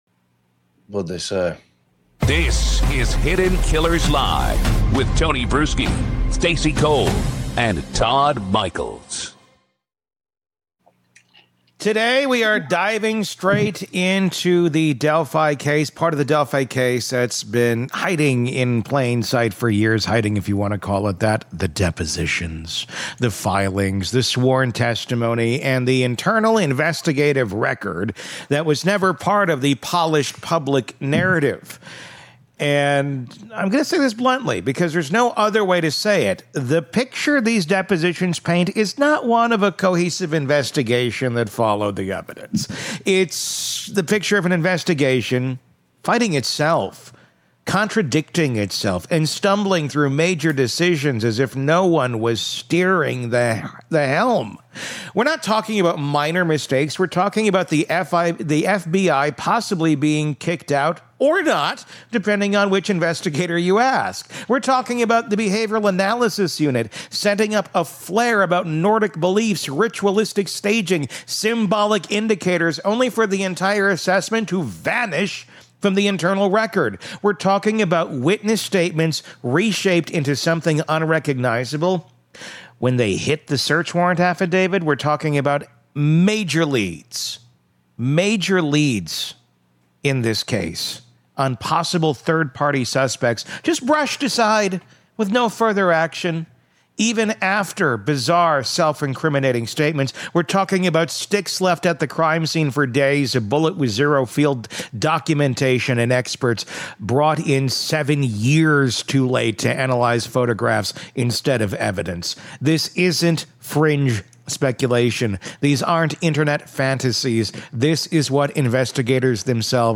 In our latest interview